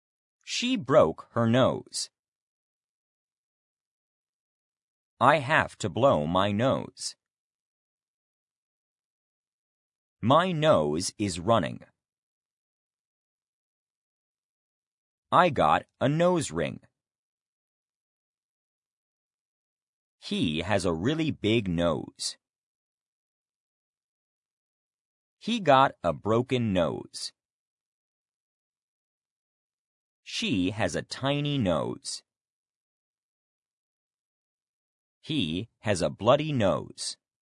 nose-pause.mp3